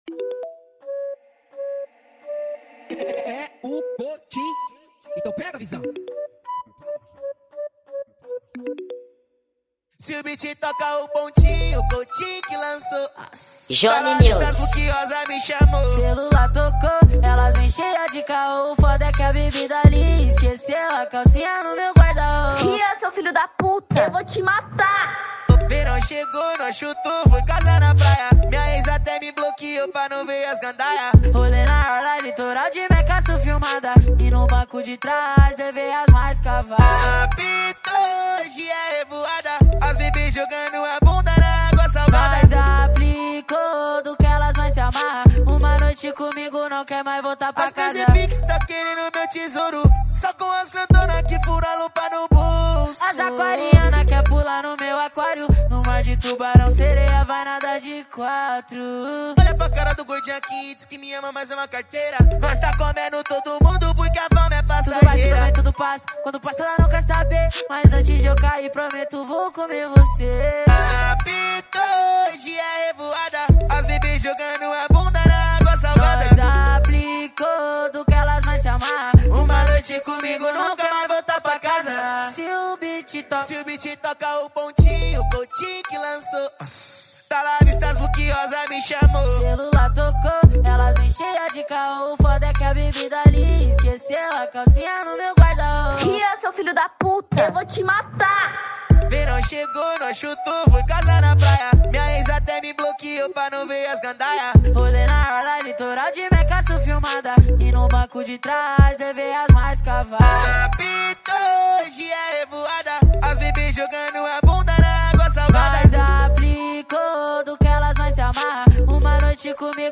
Gênero: Afro Funk